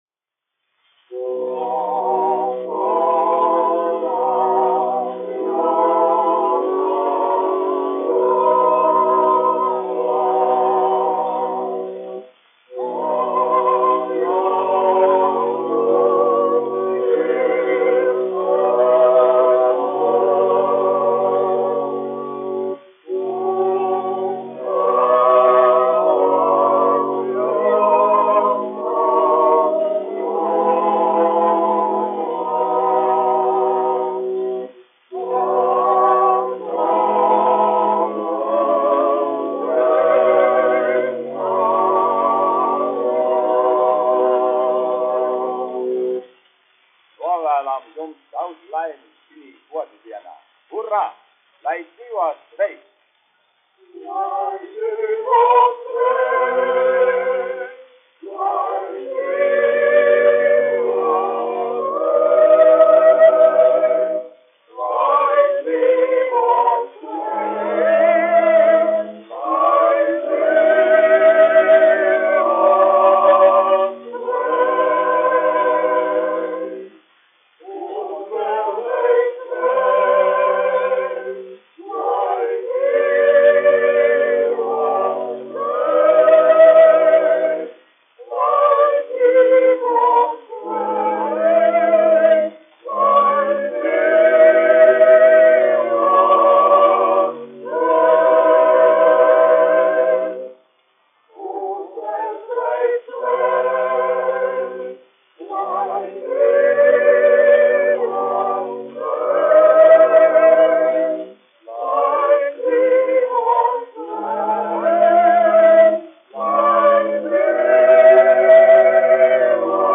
1 skpl. : analogs, 78 apgr/min, mono ; 25 cm
Kori (jauktie) ar ērģelēm
Latvijas vēsturiskie šellaka skaņuplašu ieraksti (Kolekcija)